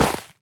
Minecraft Version Minecraft Version latest Latest Release | Latest Snapshot latest / assets / minecraft / sounds / entity / snowman / hurt2.ogg Compare With Compare With Latest Release | Latest Snapshot
hurt2.ogg